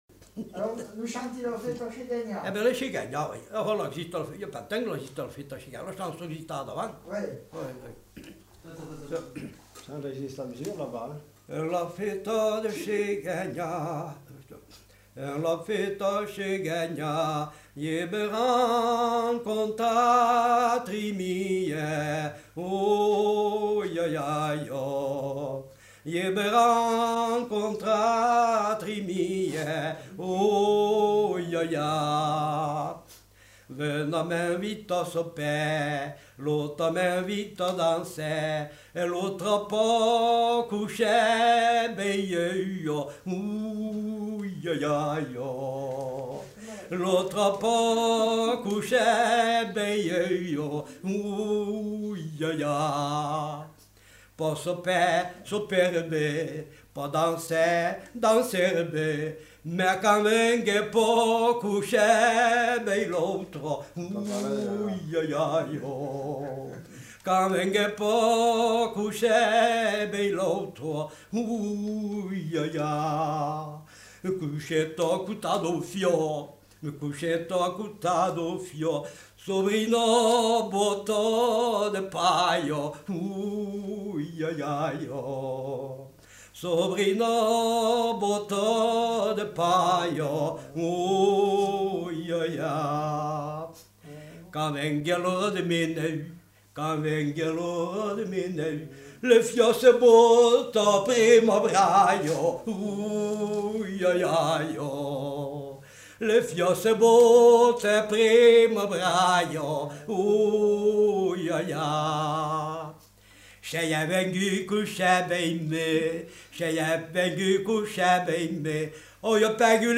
Aire culturelle : Auvergne
Lieu : Peschadoires
Genre : chant
Effectif : 1
Type de voix : voix d'homme
Production du son : chanté
Contextualisation de l'item : Chant de battage.